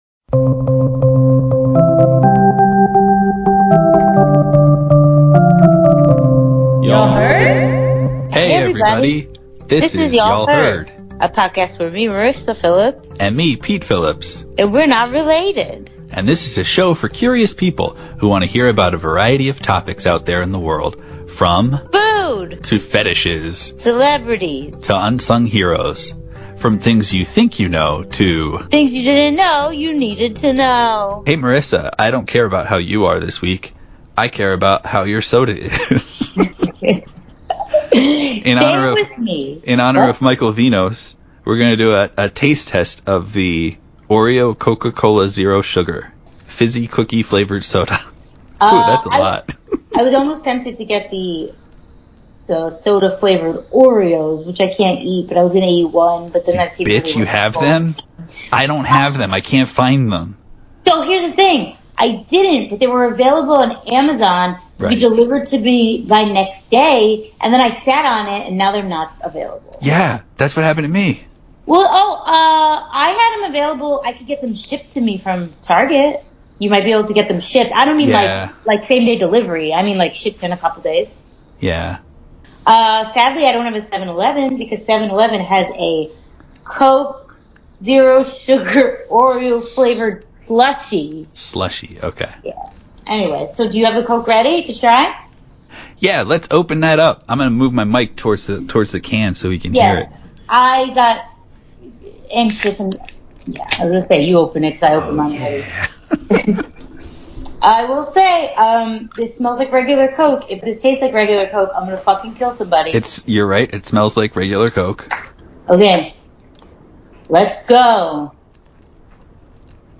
It’s here: Oreo Coke. And we’re trying it live!